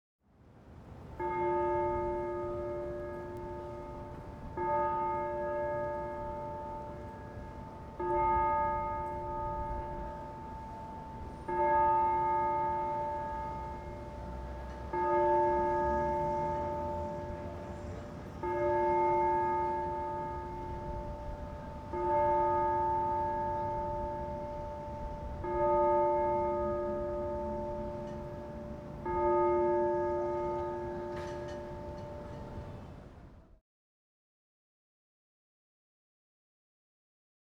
City Church Bell Sound Effect Free Download
City Church Bell